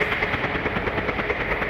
Index of /musicradar/rhythmic-inspiration-samples/140bpm